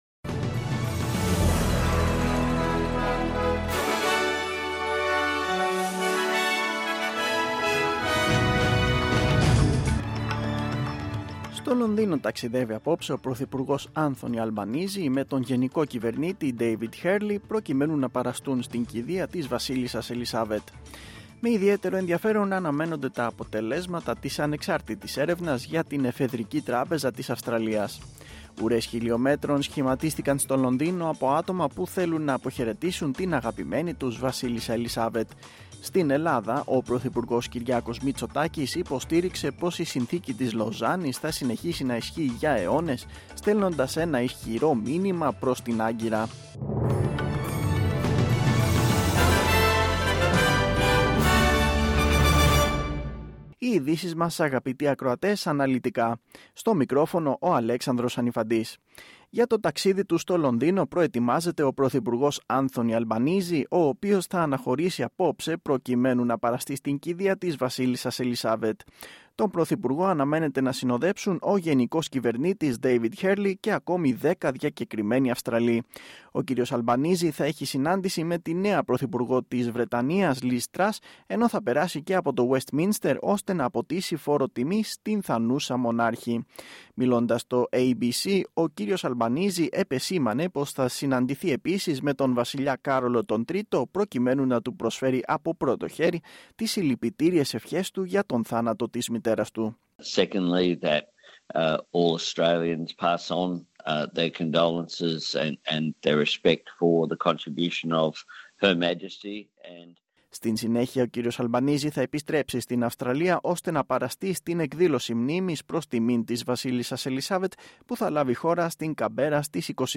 News in Greek.